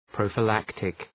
Προφορά
{,prəʋfə’læktık}
prophylactic.mp3